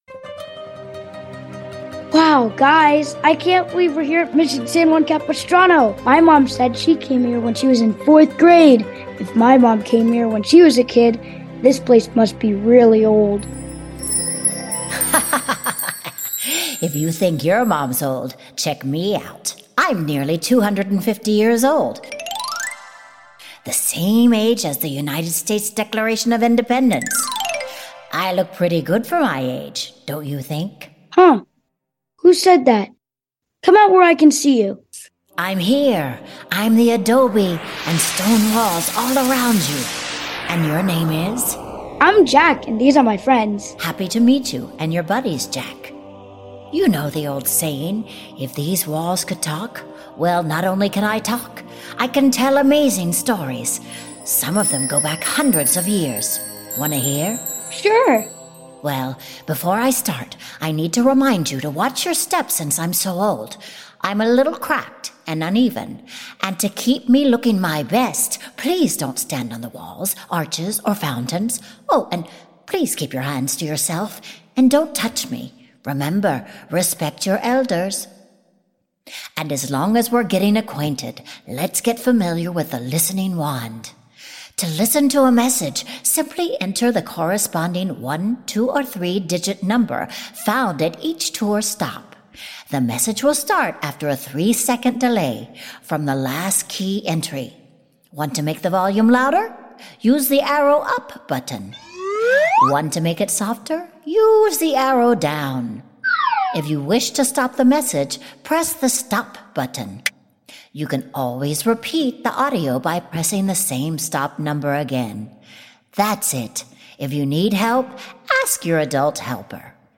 Mission San Juan Capistrano Kids’ Tour-Opening Stop Narration, Music, Sound Effects and Character Voices